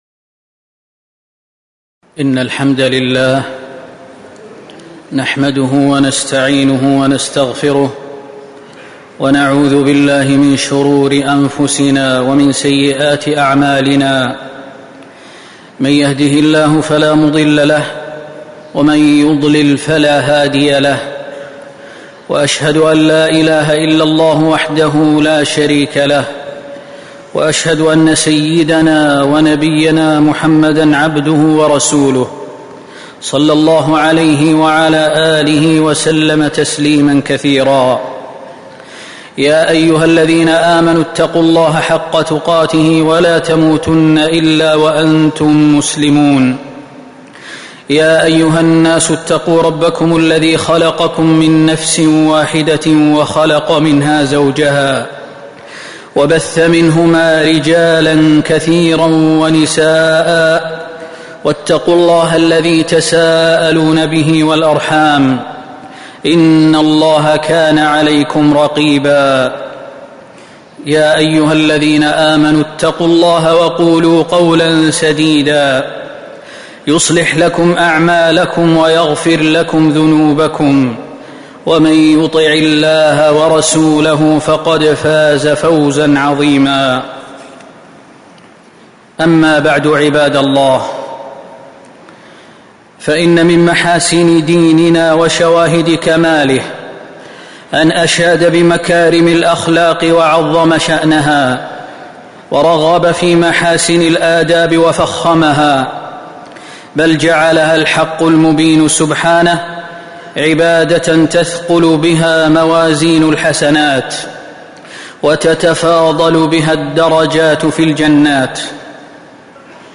تاريخ النشر ٢٤ ربيع الأول ١٤٤٦ المكان: المسجد النبوي الشيخ: فضيلة الشيخ خالد المهنا فضيلة الشيخ خالد المهنا خلق الرفق